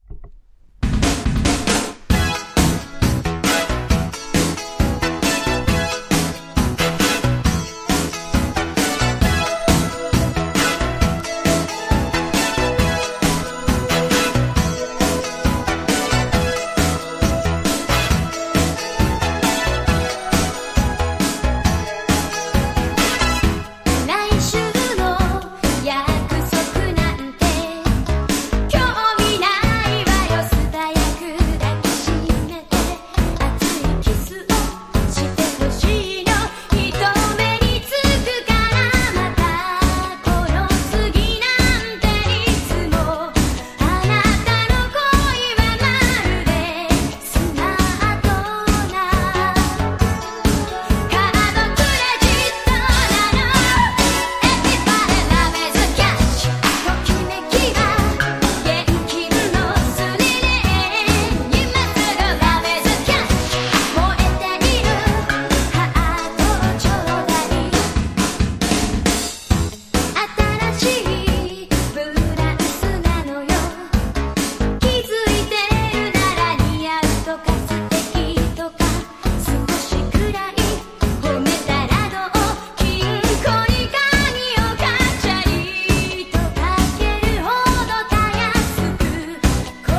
60-80’S ROCK